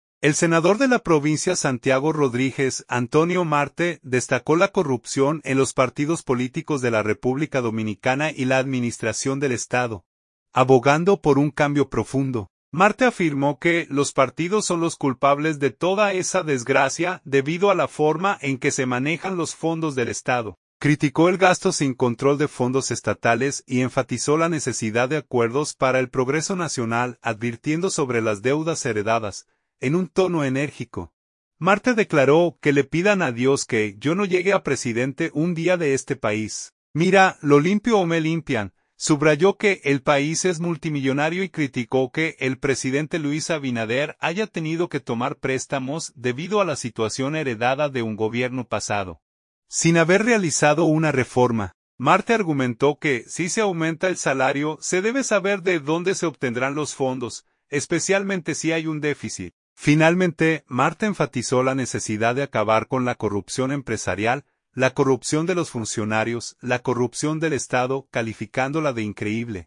En un tono enérgico, Marte declaró: «Que le pidan a Dios que yo no llegue a presidente un día de este país. Mira, lo limpio o me limpian».